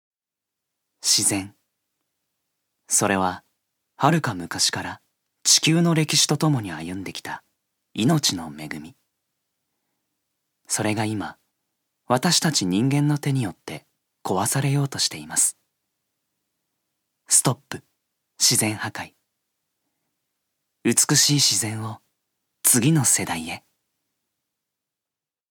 所属：男性タレント
音声サンプル
ナレーション１